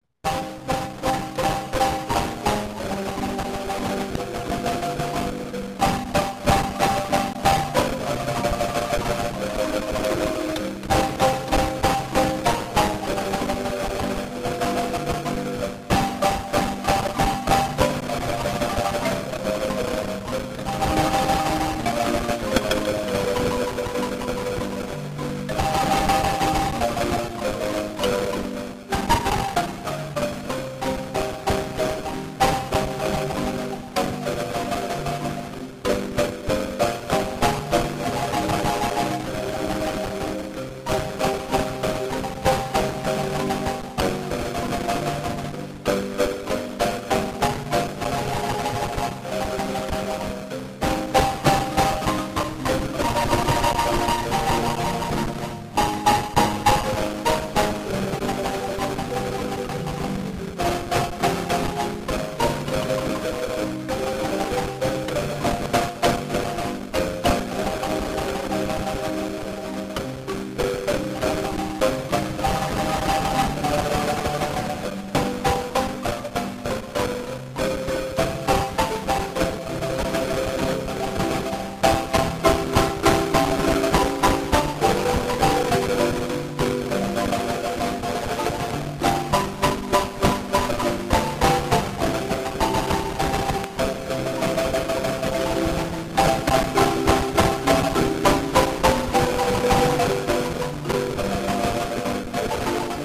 Música guanacasteca: marimba